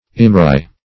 Search Result for " imrigh" : The Collaborative International Dictionary of English v.0.48: Imrigh \Im"righ\, n. [Scot.; Gael. eun-bhrigh chicken soup.] A peculiar strong soup or broth, made in Scotland.